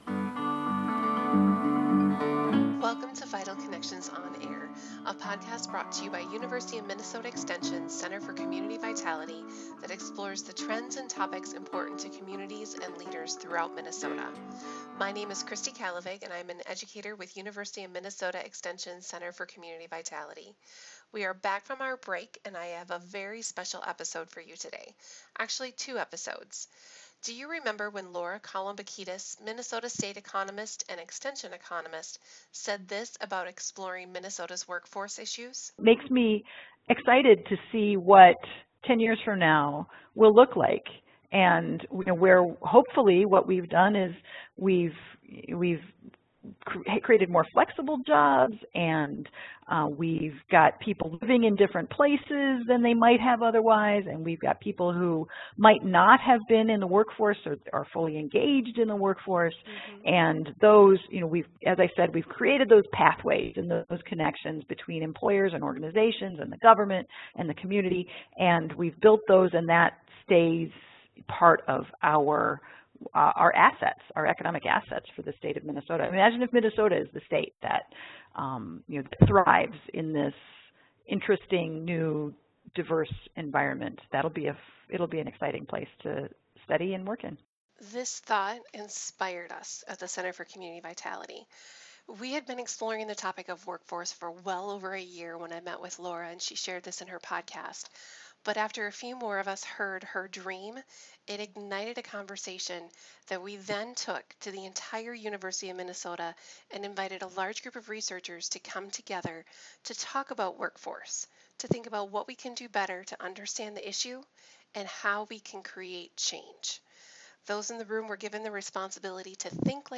This symposium encouraged those in attendance to explore the topic of workforce and consider how we might come together to better learn about and serve the people of Minnesota. In this episode you will hear from a variety of speakers who shared insights on the people being impacted by our current workforce issues and how to better prepare and help them in making vibrant economies around the state.